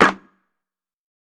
TC3Snare6.wav